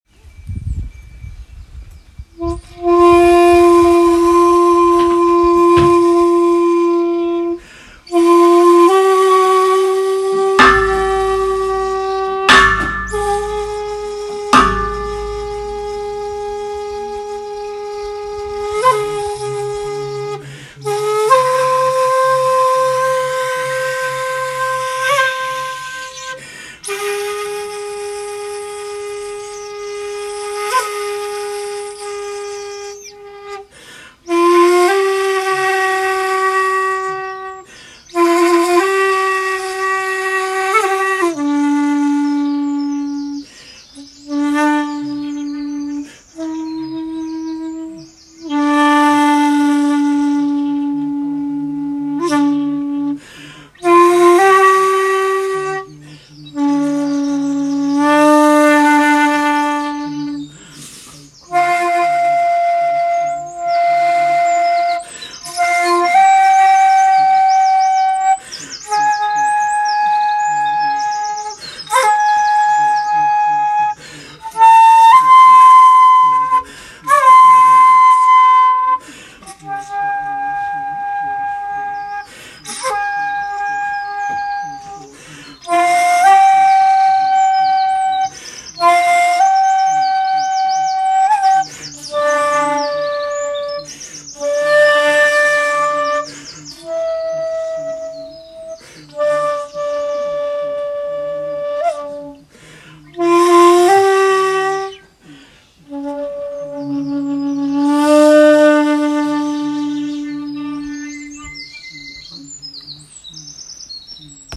ここではこれからの峠越えなどの安全を祈願し、尺八を奉納しました。
（尺八吹奏「水鏡」の音源）
（写真⑤　本堂前にて尺八吹奏）